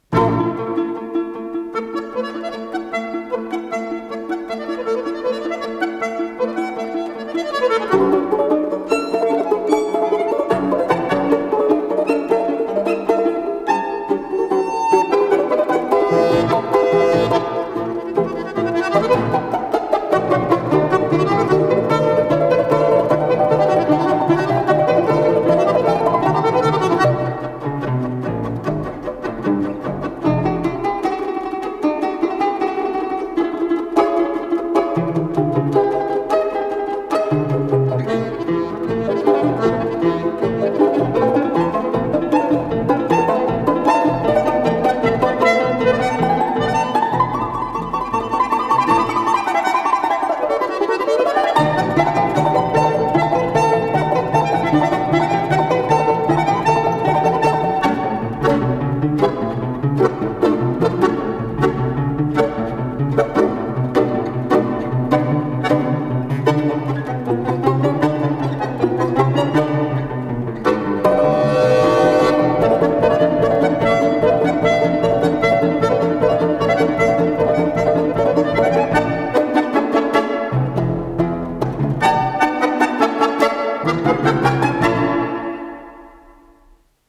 с профессиональной магнитной ленты
домра
баян
балалайка
балалайка-контрабас
ВариантДубль моно